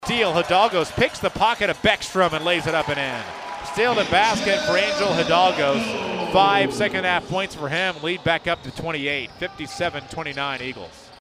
Wesleyan forced 19 York turnovers, including this one that led to an easy basket.